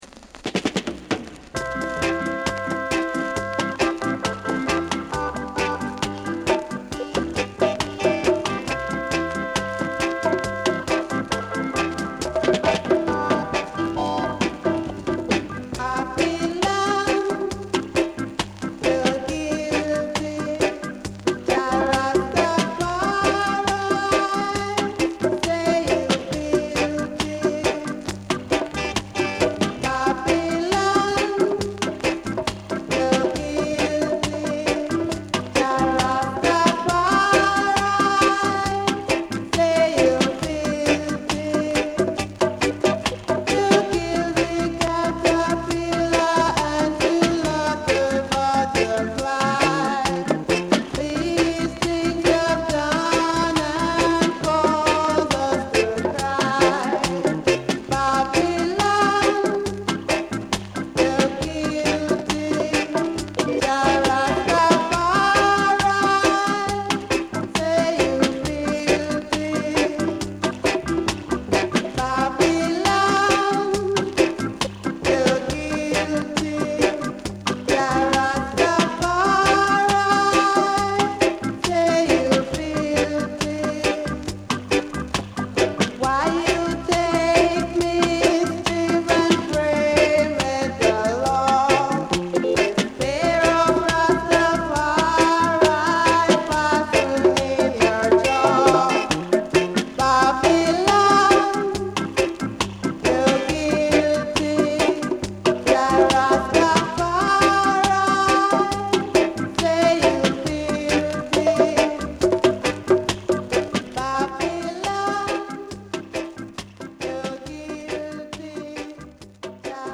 Genre: Roots Reggae